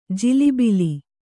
♪ jilibili